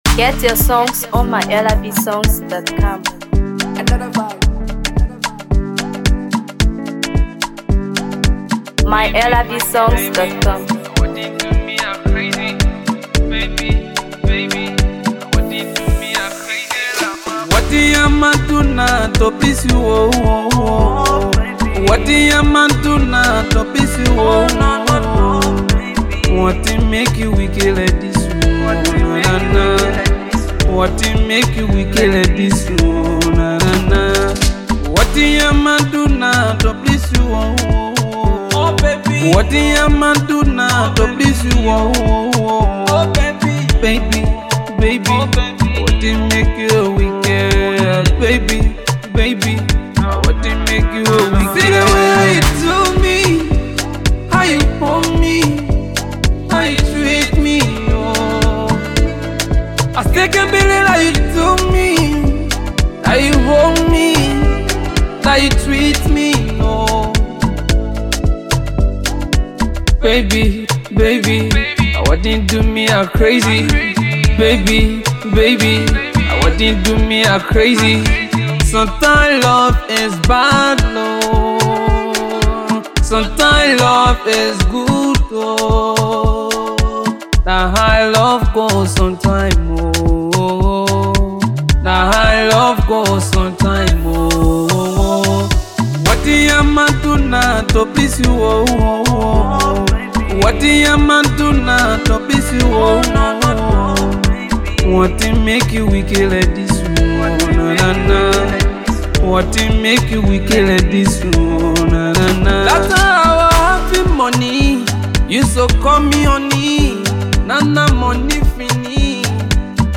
Afro PopMusic
Known for his smooth vocals and electrifying Afrobeat fusion